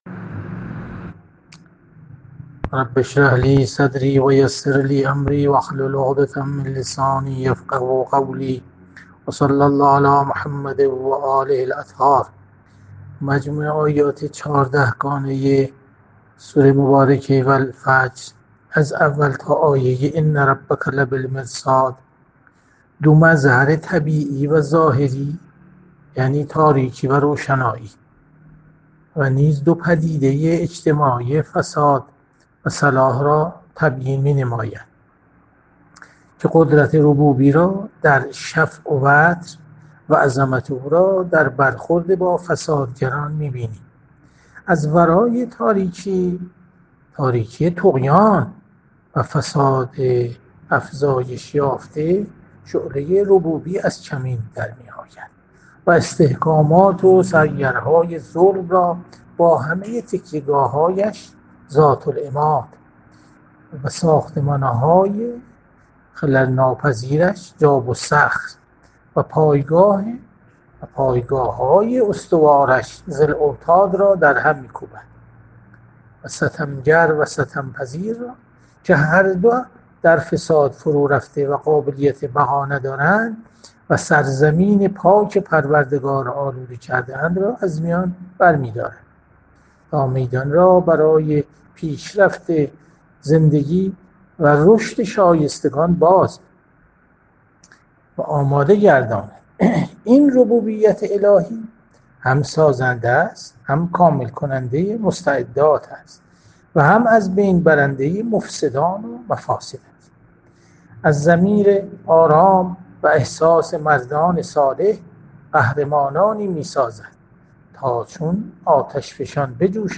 جلسه مجازی هفتگی قرآنی، سوره فجر، 07 آذر 1400